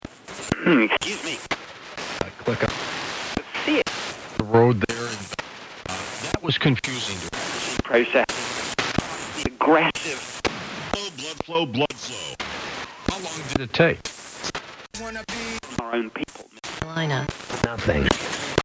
The Spirit Box swept through the otherworldly static in the atmosphere for an answer, and what we heard was quite intriguing.